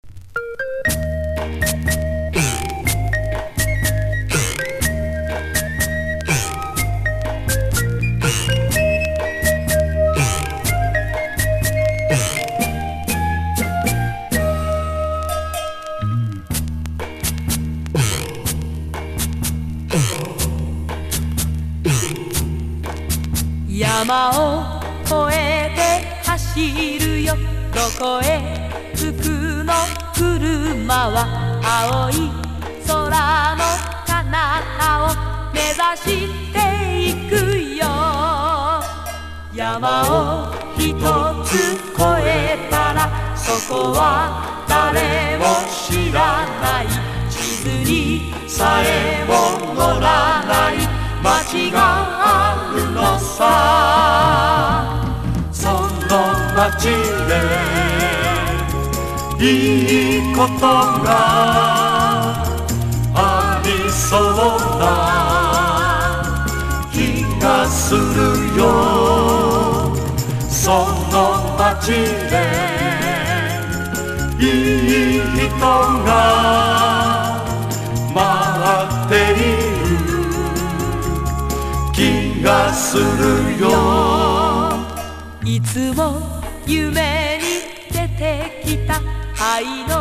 71年リリースのグルーヴィー・ソフトロックナンバー!! 美しいコーラスとPOP感が最高!!
POP